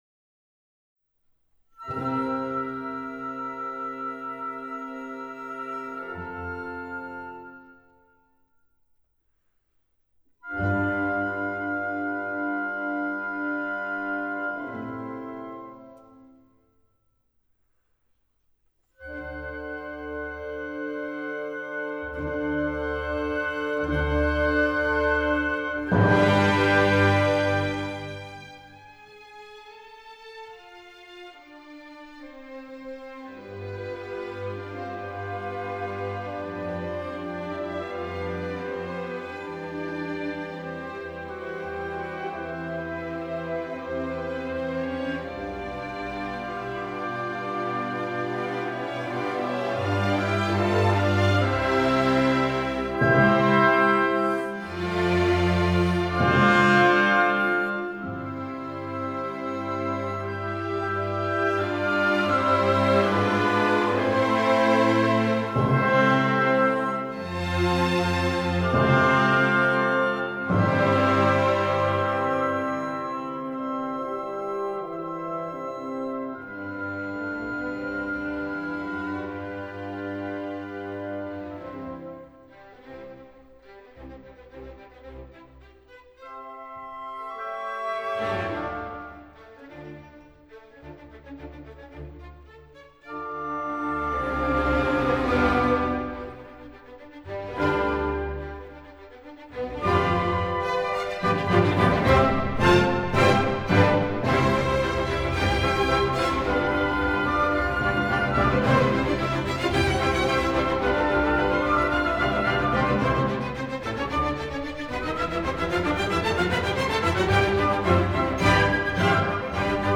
Beethoven, Symphony nº 1 – I. Adagio molto- Allegro
01-symphony-1-i-adagio-molto-allegro.m4a